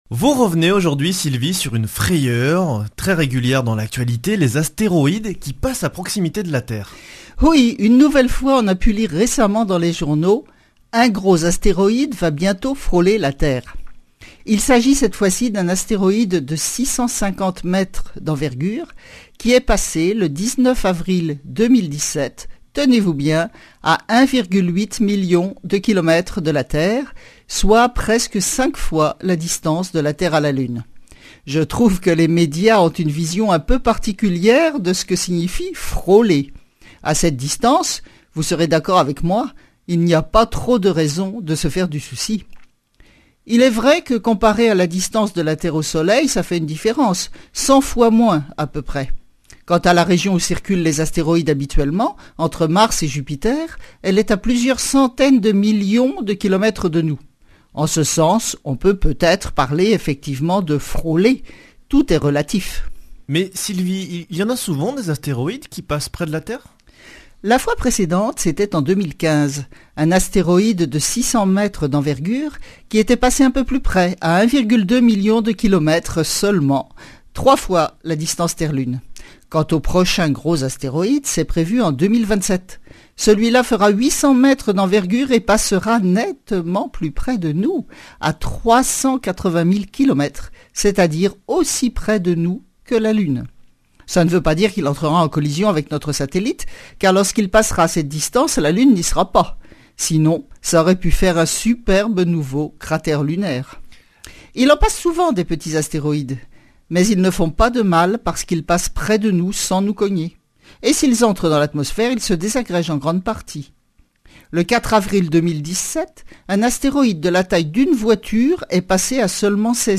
Chronique Astrophysique
Speech